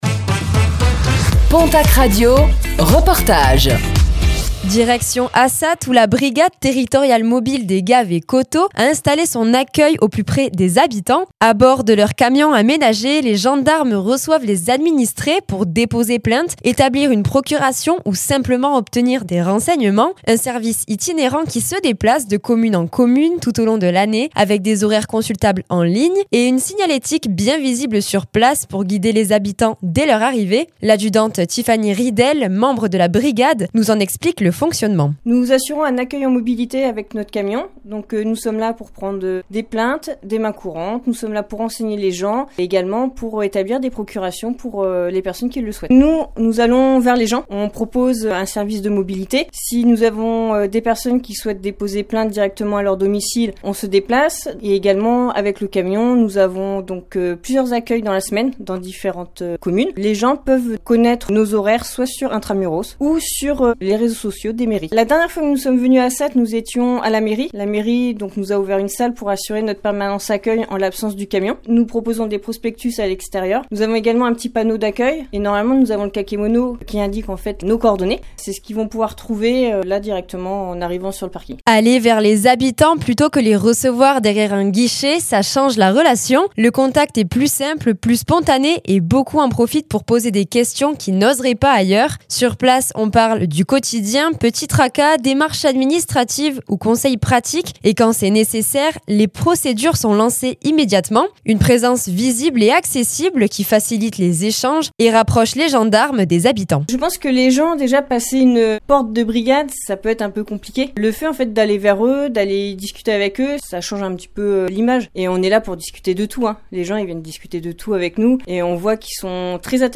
La Brigade territoriale mobile au plus près des habitants d’Assat - Reportage du mercredi 18 février 2026